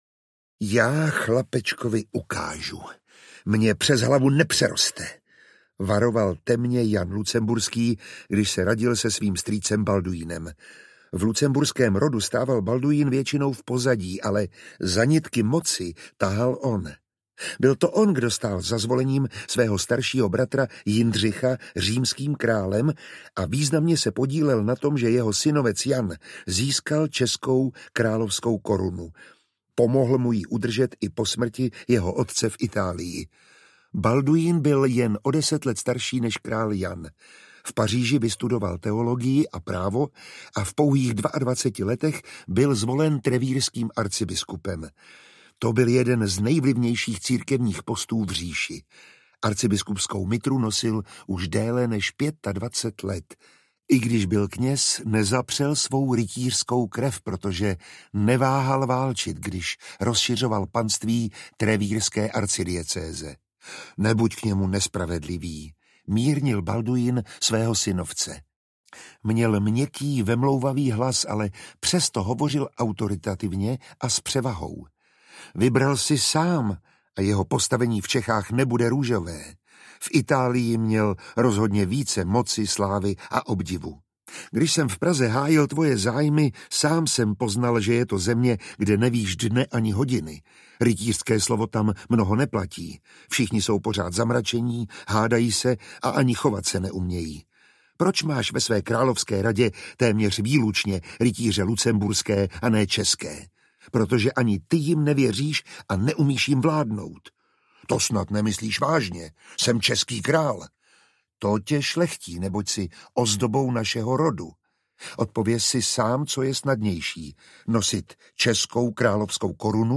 Lucemburská epopej II - Kralevic Karel (1334–1348) audiokniha
Ukázka z knihy
• InterpretMiroslav Táborský